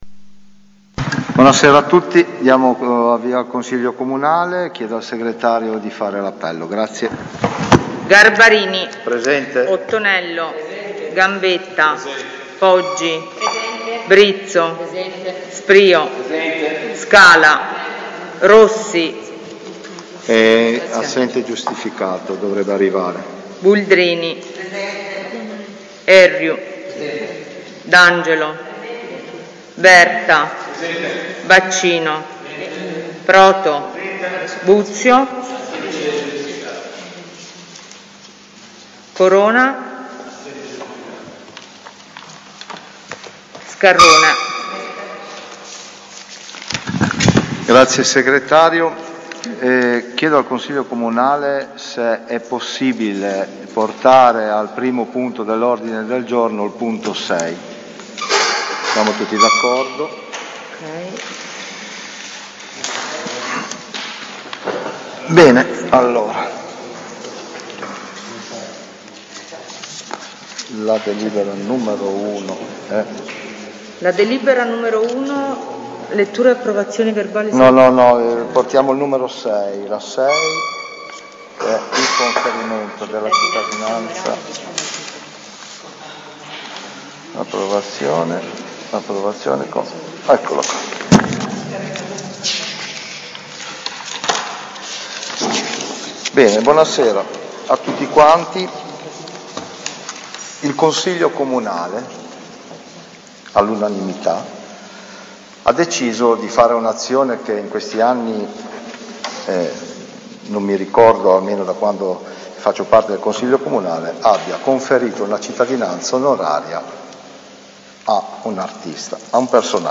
Seduta di Consiglio comunale giovedì 30 settembre 2021, alle 18.00, presso l’Auditorium Comunale di via alla Massa: